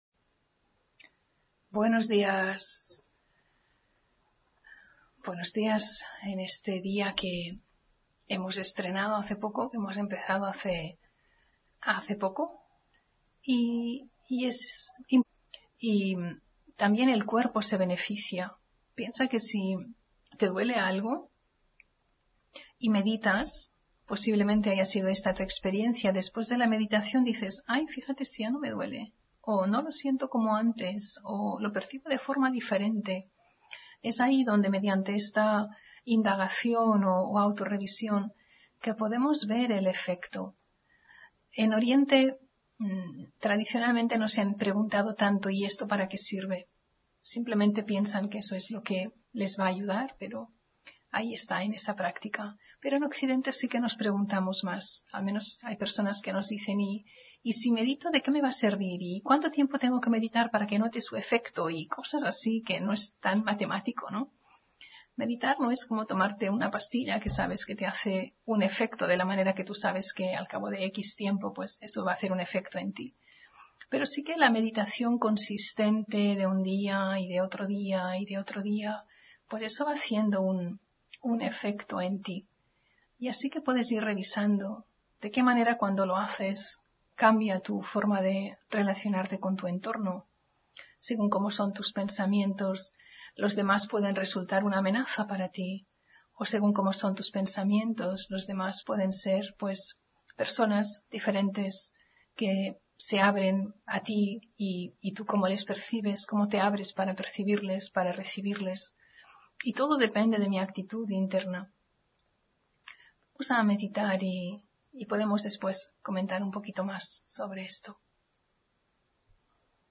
Meditación de la mañana